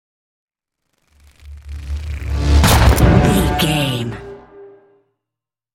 Horror whoosh to hit
Sound Effects
In-crescendo
Atonal
scary
ominous
eerie
woosh to hit